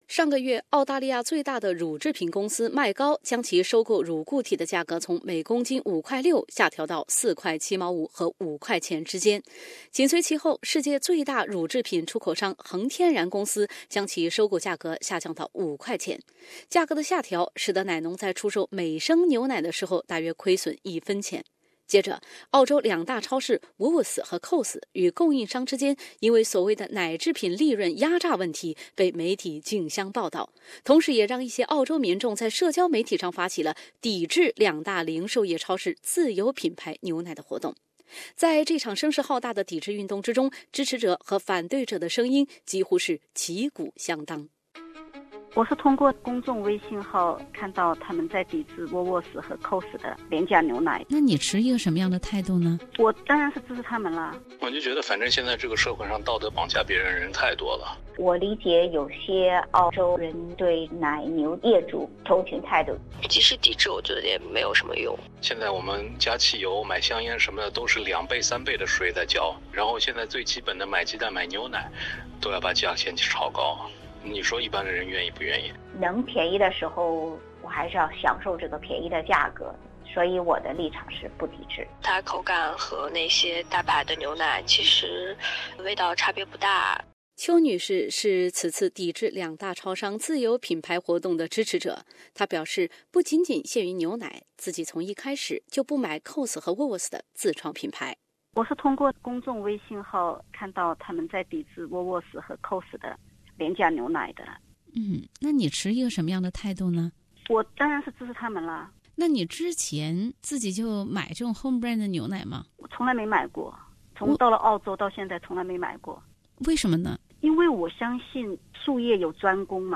深度报道：1元1升的牛奶是否该继续存在？